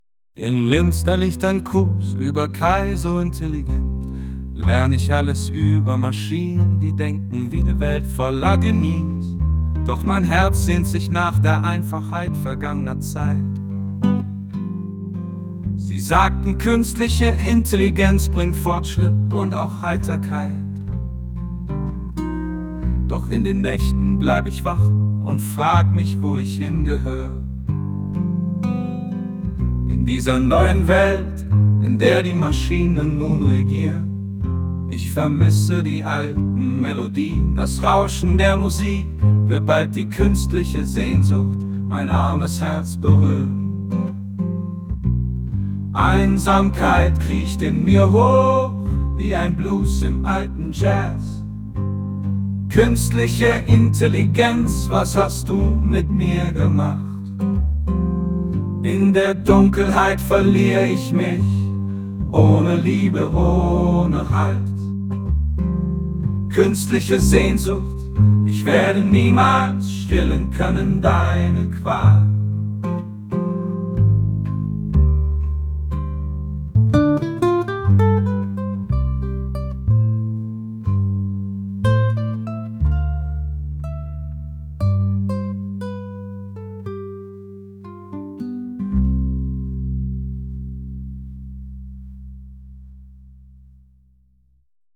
während des Workshops innerhalb von etwa zwei Minuten
mithilfe einer KI generiert
Song Künstliche Sehnsucht